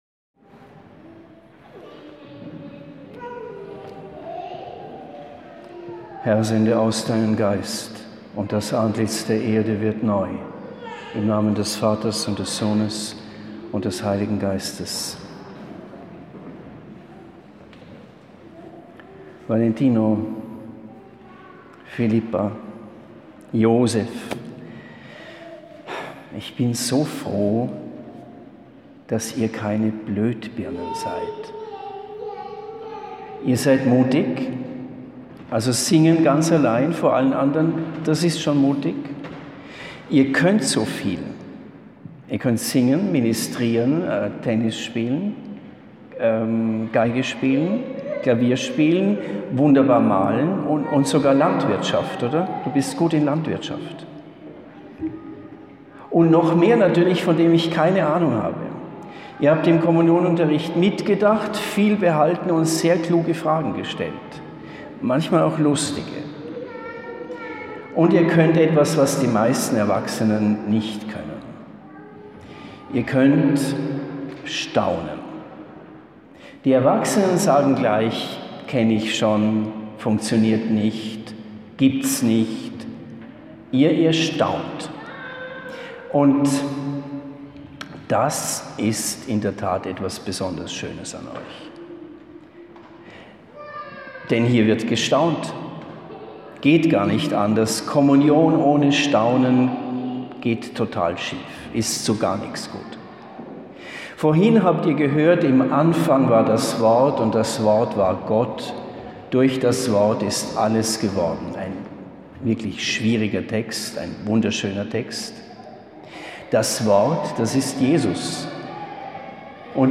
Predigt zur Feier einer Ersten Heiligen Kommunion, 16. Oktober 2021